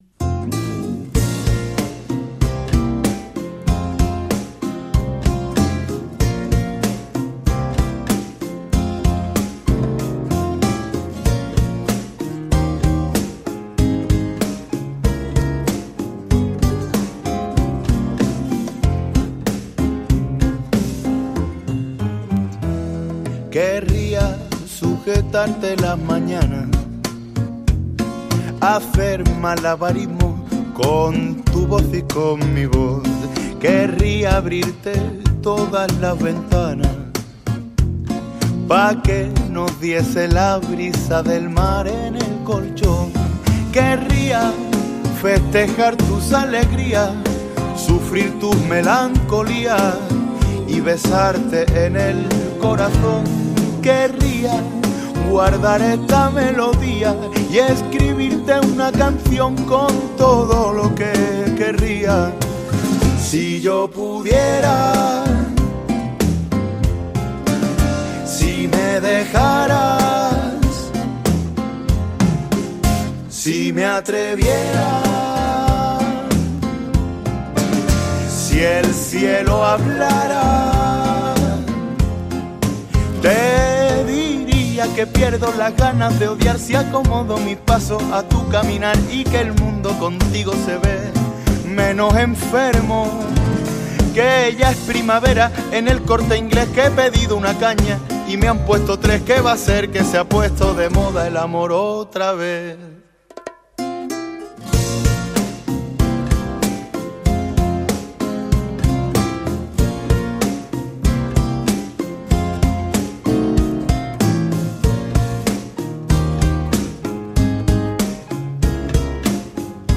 Audio: El Kanka, entrevista, Aqui Macondo